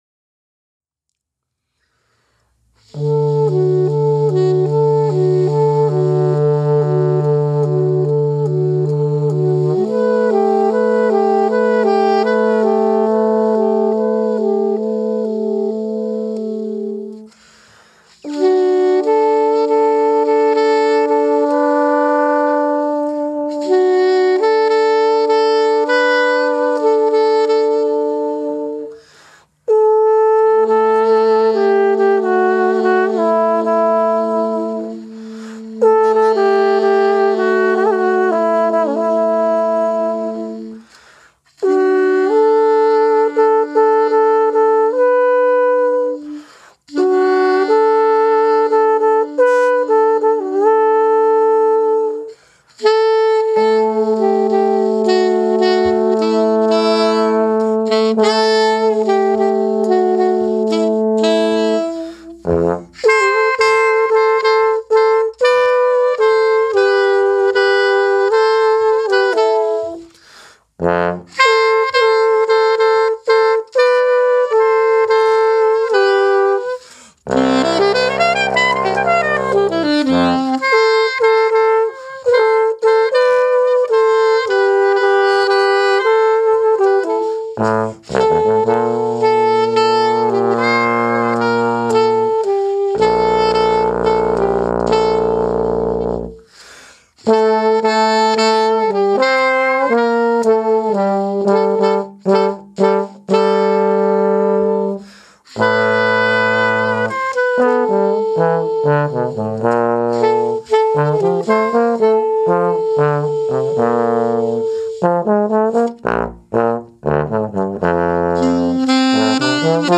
Ausnahmsweise spielen wir in dieser Folge mal ein Cover.